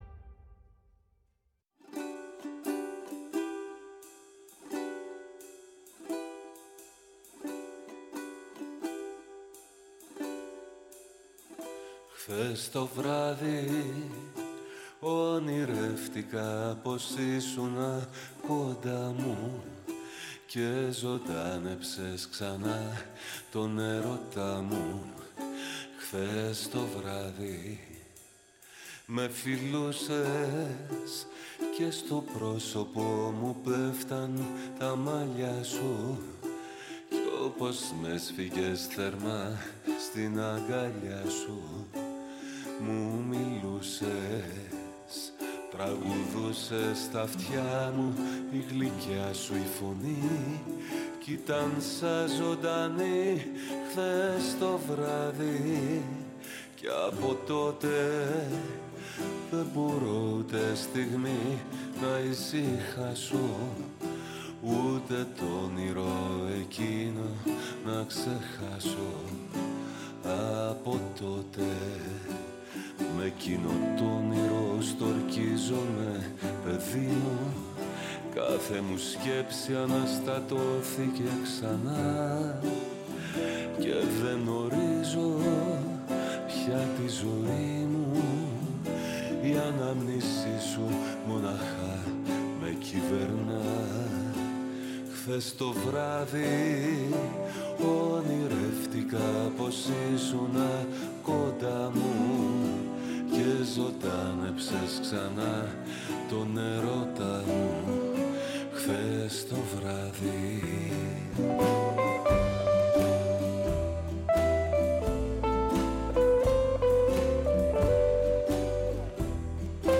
Στην εκπομπή φιλοξενήθηκε ο μουσικοσυνθέτης Δημήτρης Μαραμής και μίλησε για τη μουσική παράσταση “Ο τελευταίος έρωτας” με τον ερμηνευτή Θοδωρή Βουτσικάκη και την ηθοποιό Καρυοφυλλιά Καραμπέτη που θα κάνει πρεμιέρα στις Βρυξέλλες το Σάββατο 21 Σεπτεμβρίου 2024.